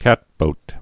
(kătbōt)